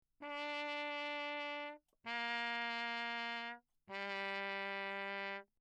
13 Spitzdämpfer (Straight Mutes) für Trompeten im Klangvergleich
Im Rahmen dieser Arbeit wurden verschiedene kurze Sequenzen zunächst ohne Dämpfer und dann mit dreizehn verschiedener Spitzdämpfer im reflexionsarmen Raum der mdw aufgenommen.
Perinétventiltrompete
Tiefes Register
TRP-MUTE_Bach_Engemann_low.mp3